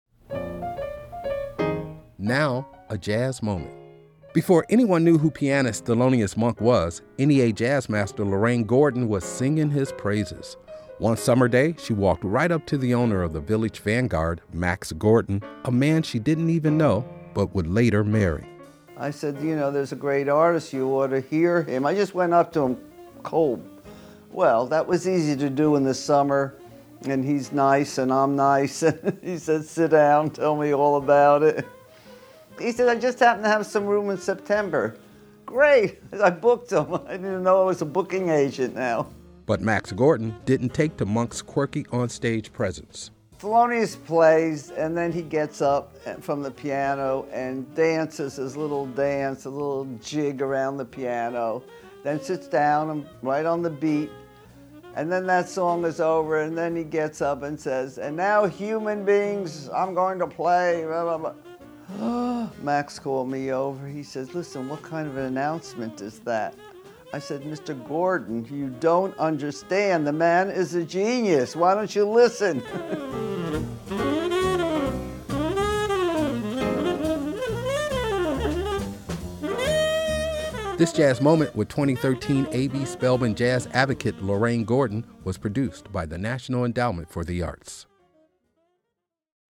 MUSIC CREDIT: Excerpt of "Blues Five Spot" composed and performed by Thelonious Monk from Misterioso, used by courtesy of Concord Music Group and by permission of Thelonious Music Corp (BMI).